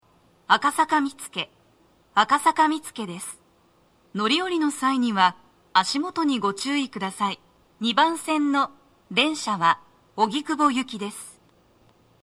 足元注意喚起放送が付帯されています。
女声
到着放送1